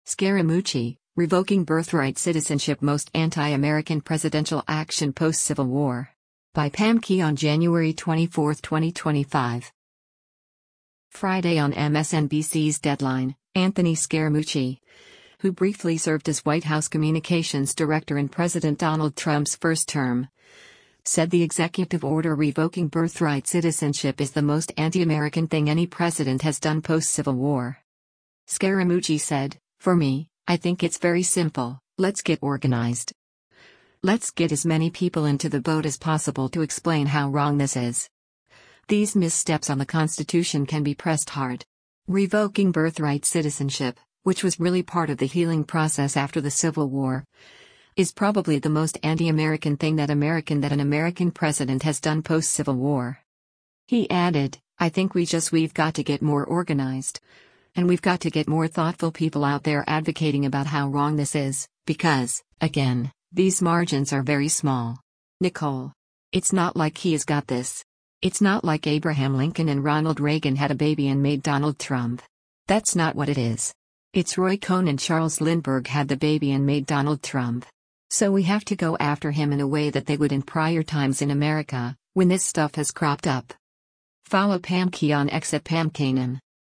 Friday on MSNBC’s “Deadline,” Anthony Scaramucci, who briefly served as White House communications director in President Donald Trump’s first term, said the executive order revoking birthright citizenship is the “most anti-American thing” any president has done post-Civil War.